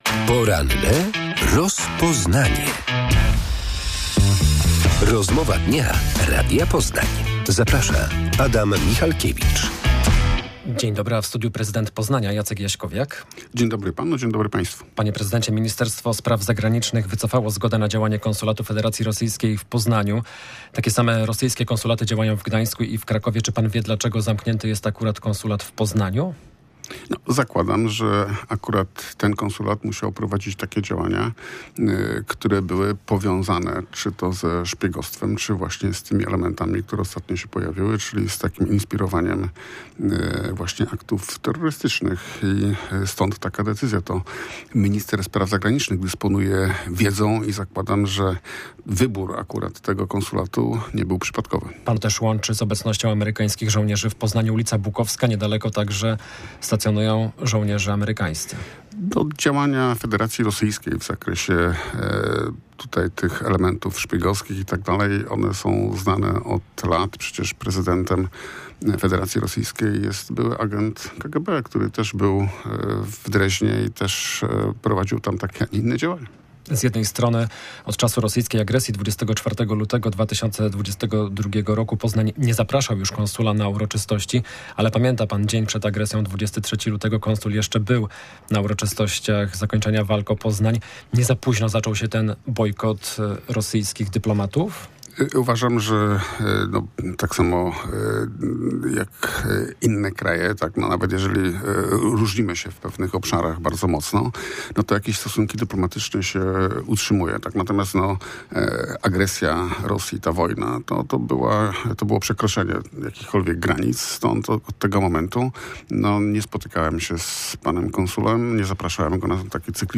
Gościem Radia Poznań był prezydent Poznania Jacek Jaśkowiak, z którym rozmawialiśmy o konsulacie rosyjskim, Osiedlu Maltańskim i przedsiębiorcach z ulicy Kraszewskiego.